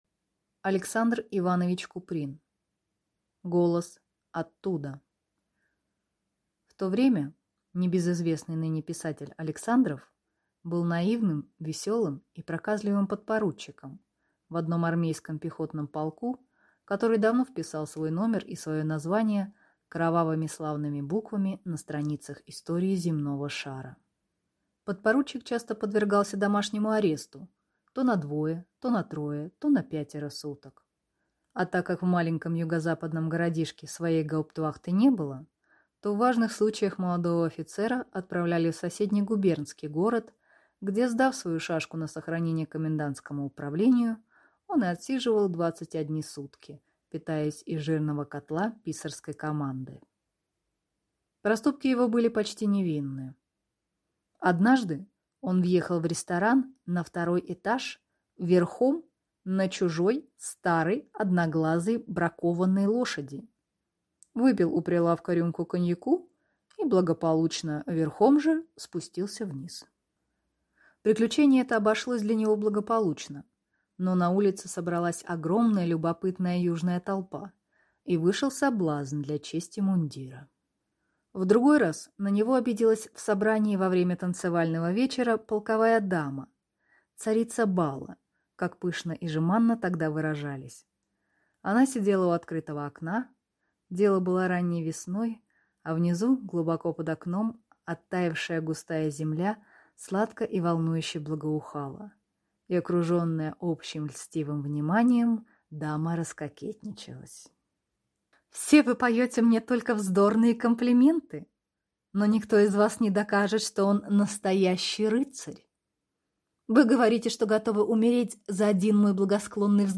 Аудиокнига Голос оттуда | Библиотека аудиокниг